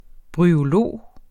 Udtale [ bʁyoˈloˀ ]